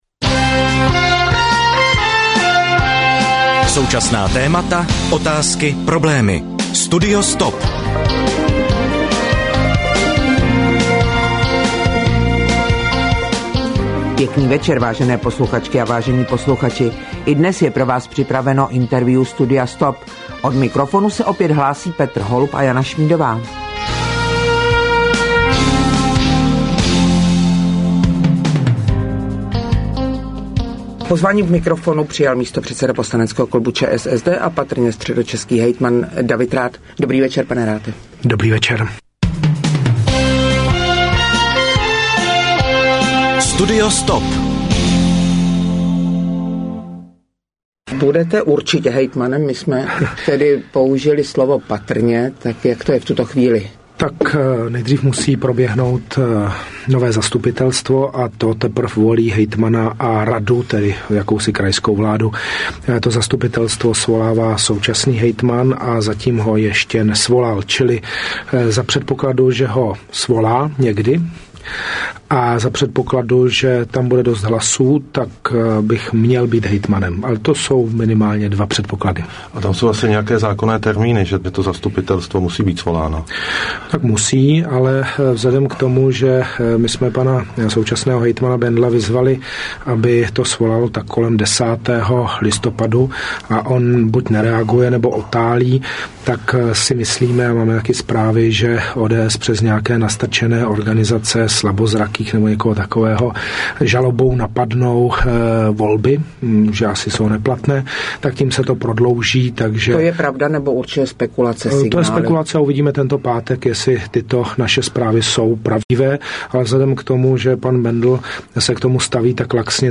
CRo 6: Rozhovor s Davidem Rathem